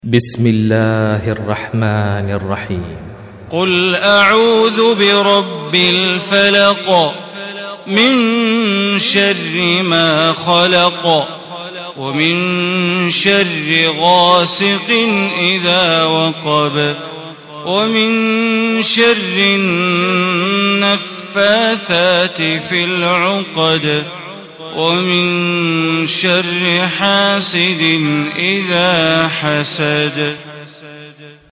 Surah Al Falaq Recitation by Sheikh Bandar Baleela
Surah Al Falaq, listen or play online mp3 tilawat / recitation in arabic in the beautiful voice of Sheikh Bandar Baleela.